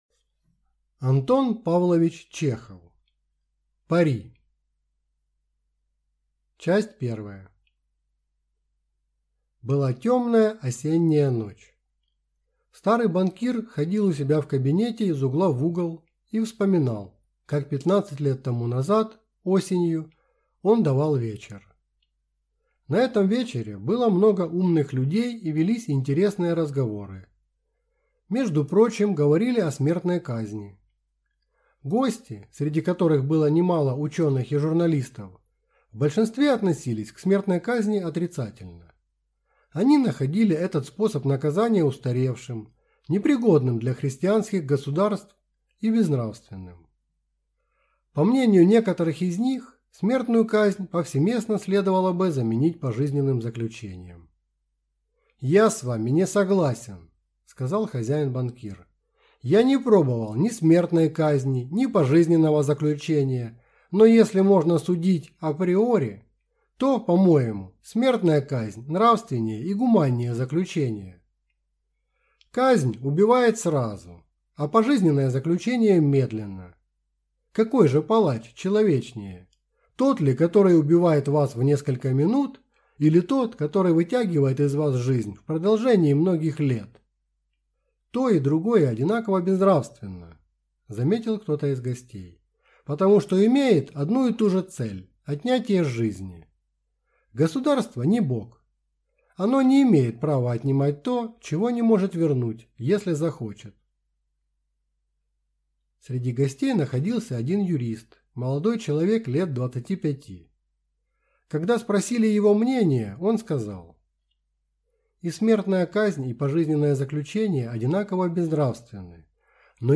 Аудиокнига Пари | Библиотека аудиокниг